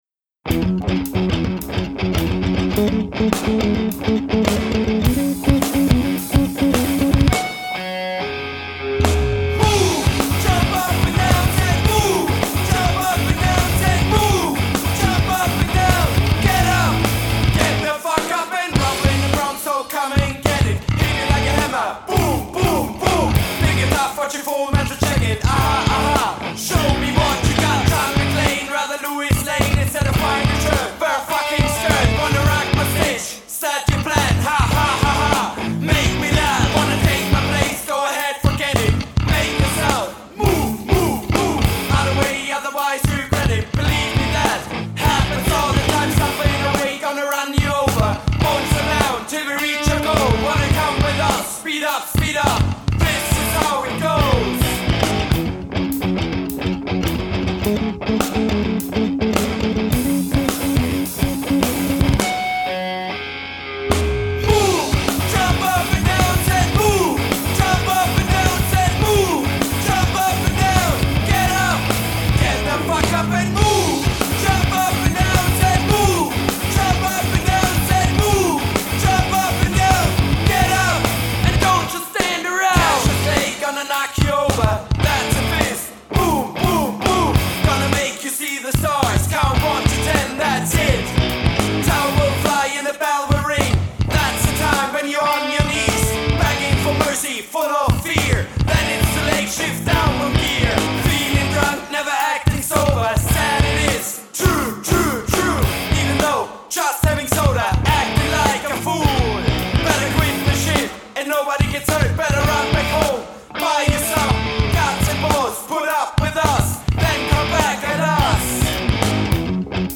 new crossbeat Hands in the air
Vocals
Guitar
Drums